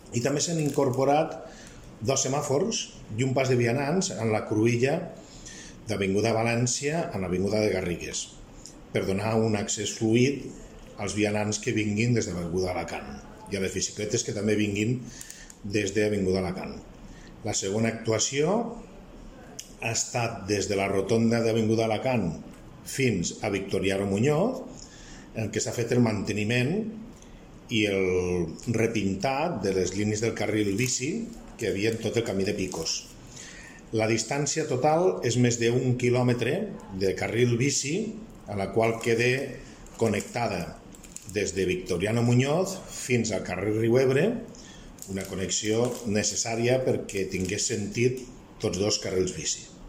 tall-de-veu-del-regidor-joan-ramon-castro-sobre-el-carril-bici-a-lavinguda-de-valencia